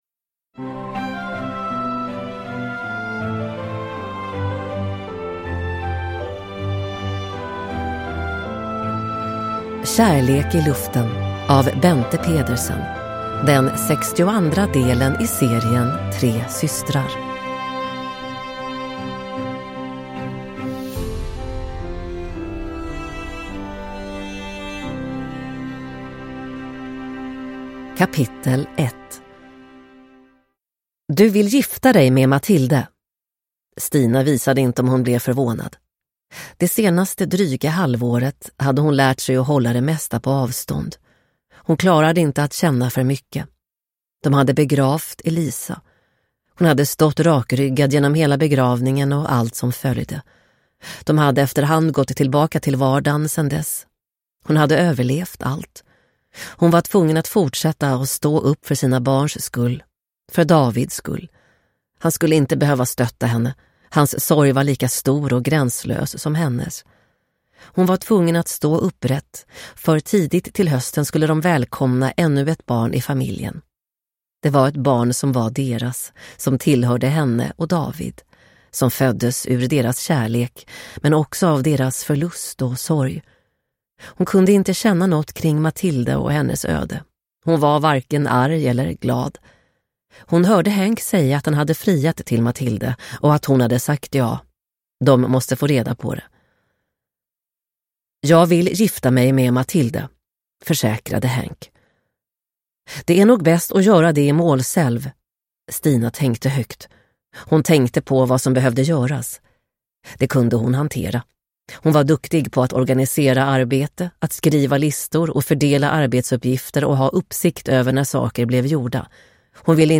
Kärlek i luften – Ljudbok – Laddas ner